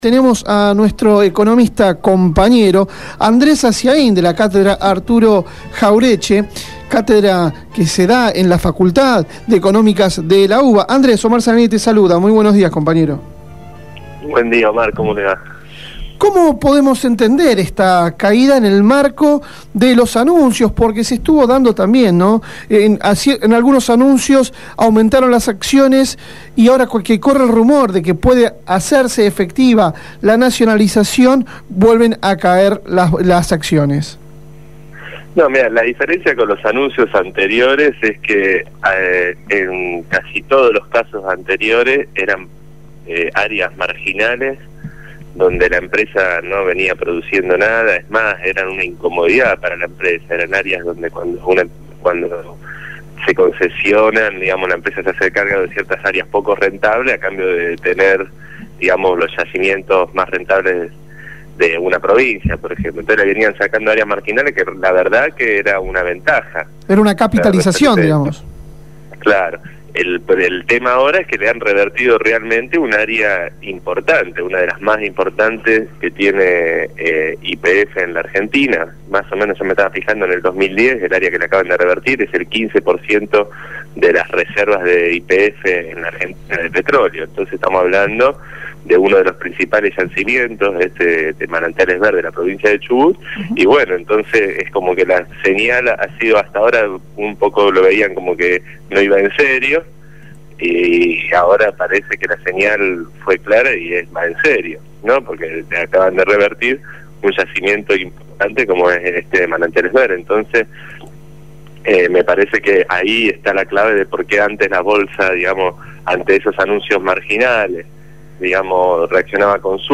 economista